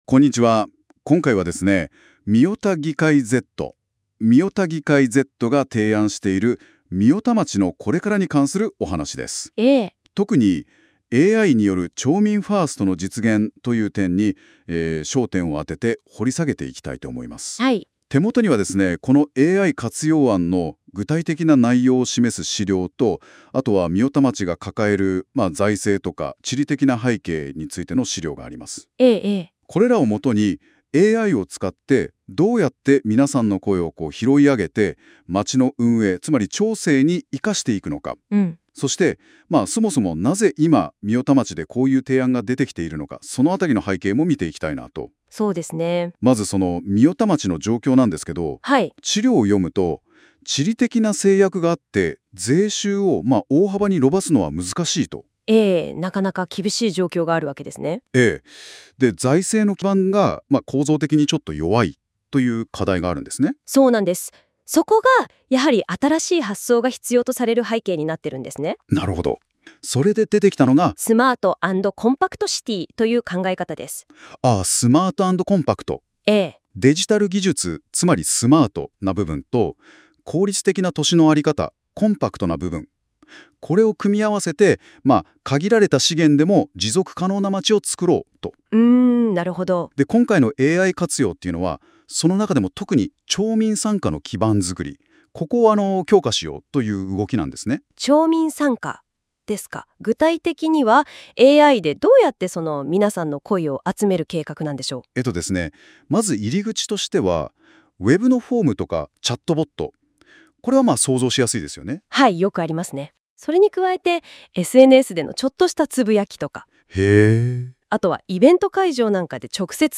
音声解説
AI生成なので固有名詞などの誤読がありますがご了承ください。